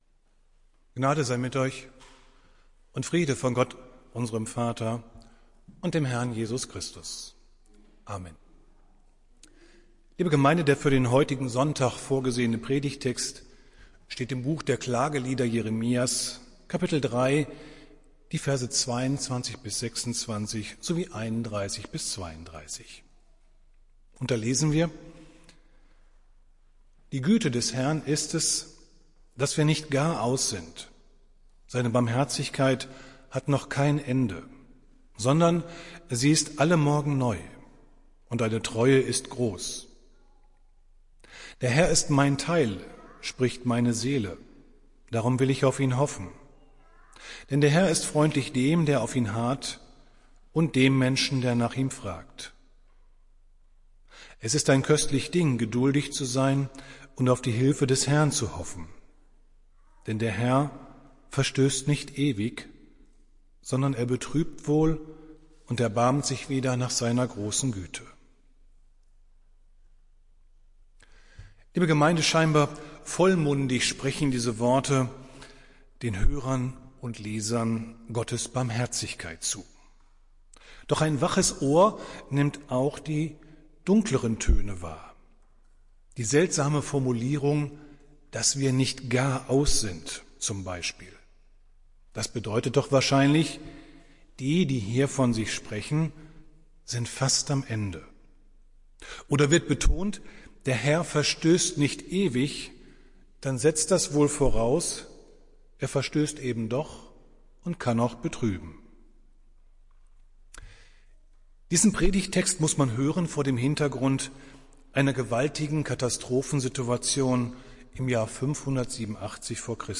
Predigt des Gottesdienstes aus der Zionskirche vom Sonntag, 19.09.2021